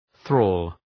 Προφορά
{ɵrɔ:l}